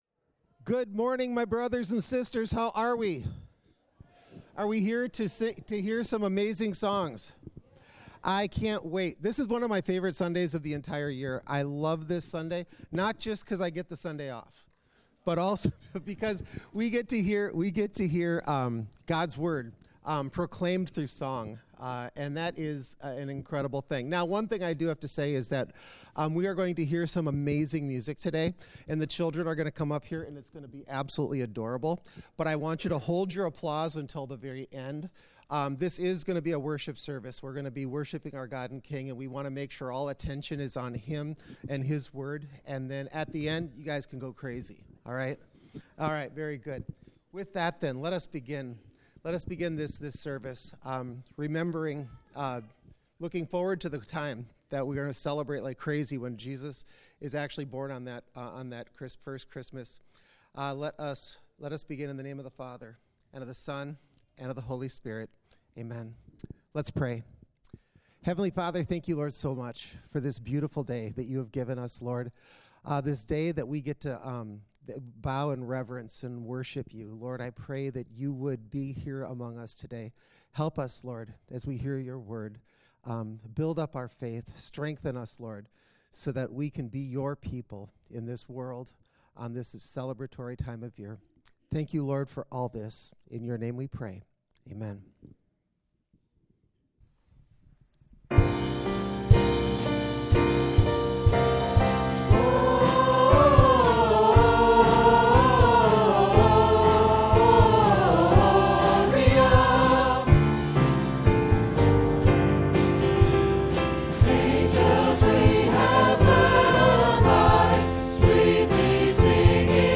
12 14 25 Third Sunday of Advent (Cantata) - St. Mark's Lutheran Church and School